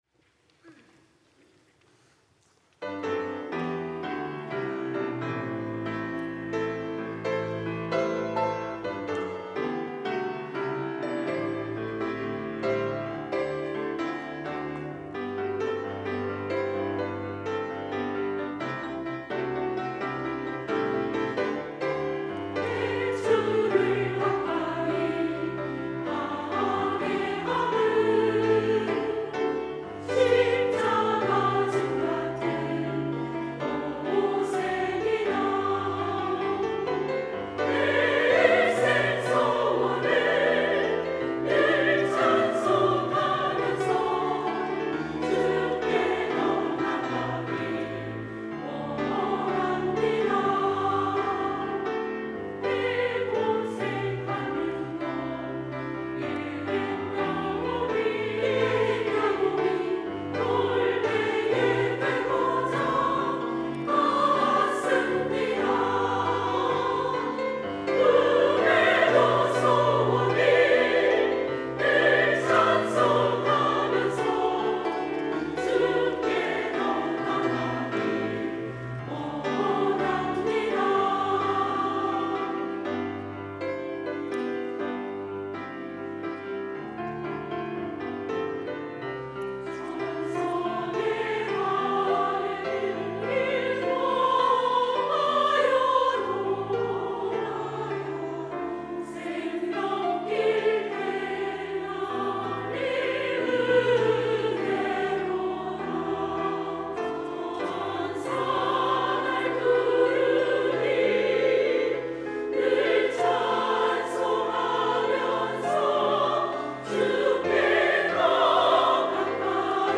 여전도회 - 내 주를 가까이